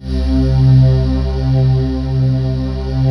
Index of /90_sSampleCDs/USB Soundscan vol.28 - Choir Acoustic & Synth [AKAI] 1CD/Partition D/23-SOMEVOICE